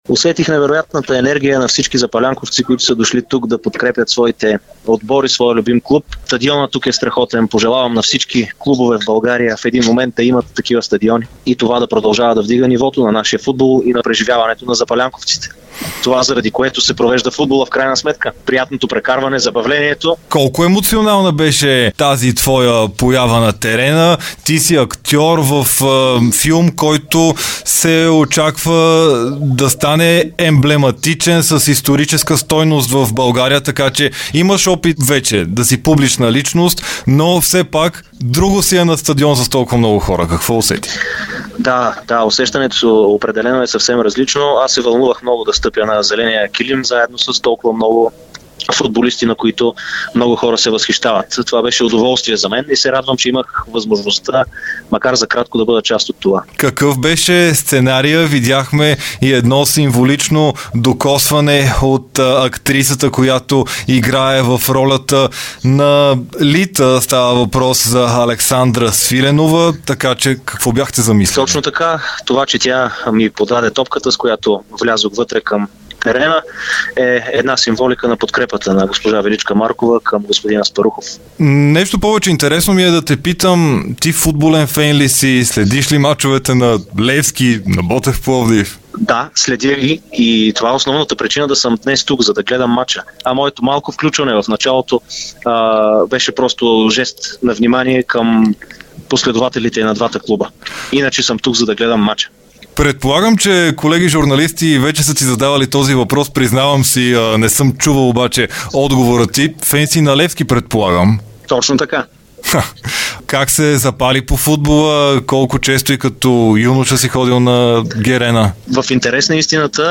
в интервю за Дарик радио и dsport.